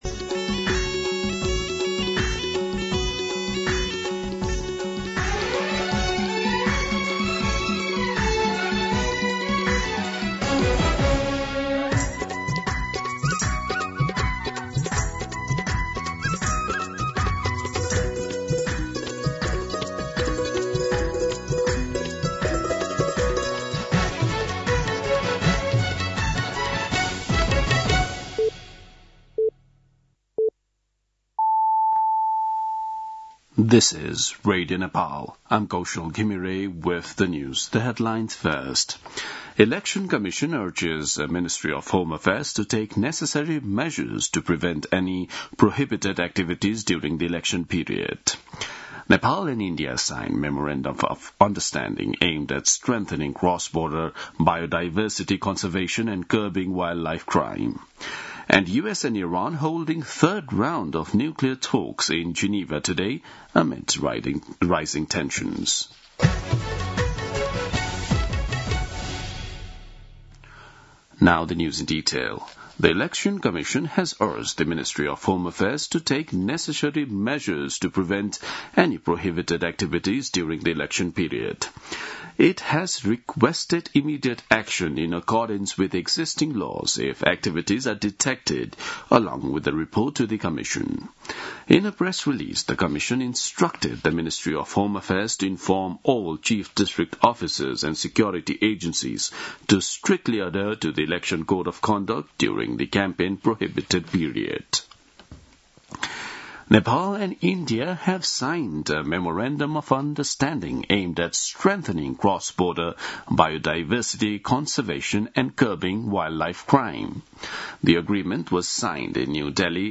दिउँसो २ बजेको अङ्ग्रेजी समाचार : १४ फागुन , २०८२